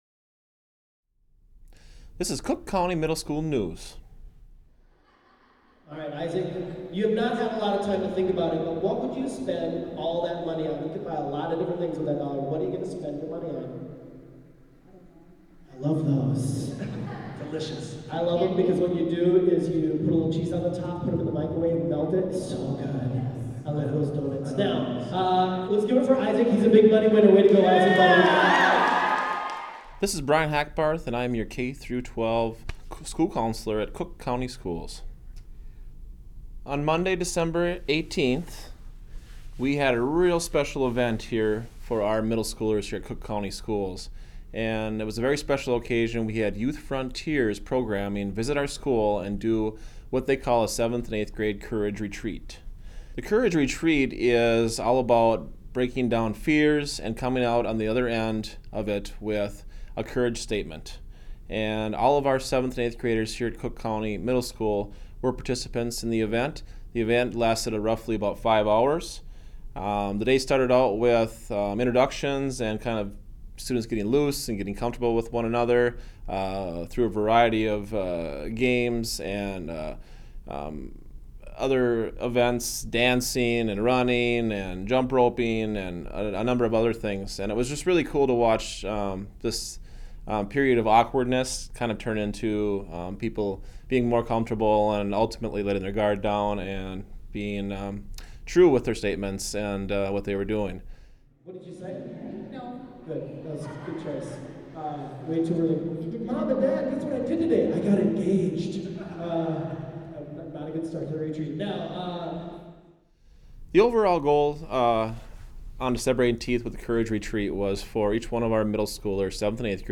School News